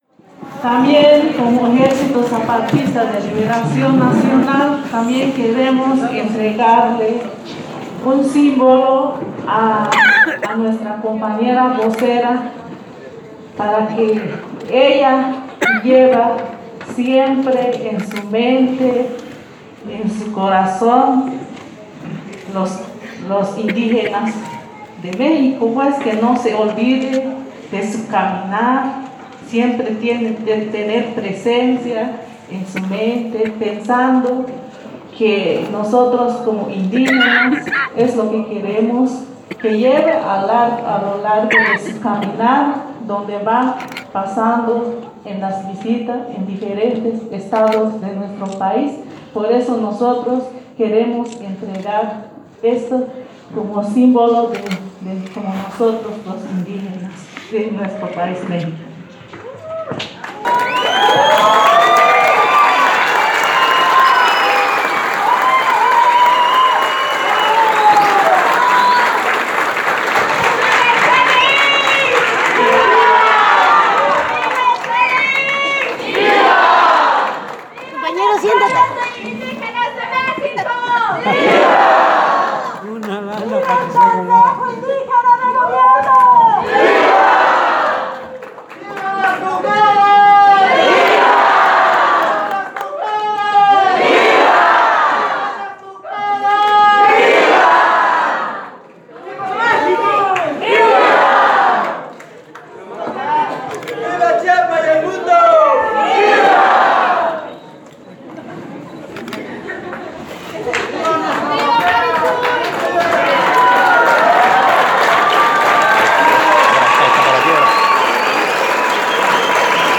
Aquí les compartimos los audios de la sesión pública de la Asamblea Constitutiva del Consejo Indígena de Gobierno para México, realizada el 28 de mayo de 2017 en el CIDECI-Unitierra en San Cristóbal de las Casas, Chiapas: